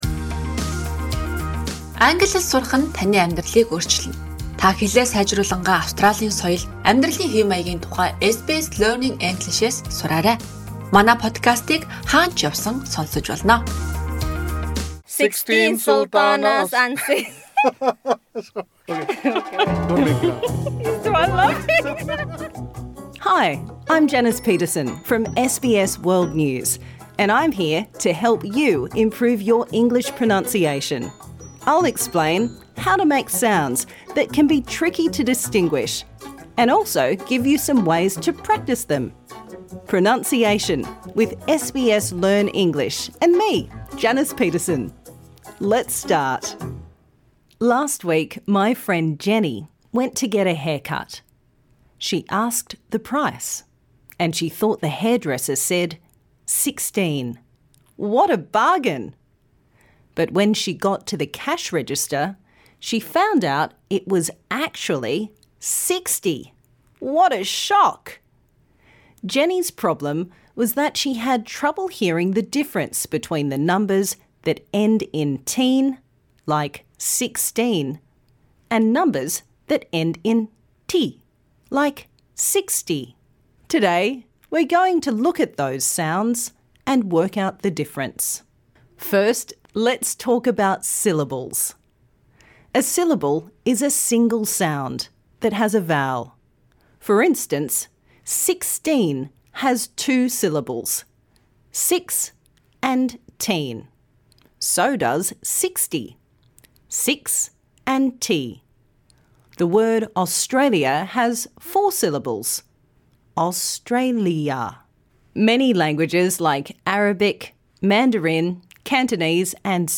Key Points Learning Objectives: understanding stress patterns and practicing the difference between numbers ending in -teen or -ty L1 Influence: many language learners struggle with ‘teen’ and ‘ty’ because English is stress timed, not syllable timed. Text for Practise : I went to the store and bought thirteen tomatoes for thirty dollars, fourteen fish for forty dollars, fifteen forks for fifty dollars, etc. Minimal Pairs : ‘teen’ is the stressed syllable - it is long and clear and the /t/ is clearly pronounced: thirteen, fourteen... ‘ty’ is the unstressed syllable- it is short and quick and the ‘t’ is pronounced /d/: thirty, forty, fifty...
This lesson suits all learners looking to improve their pronunciation.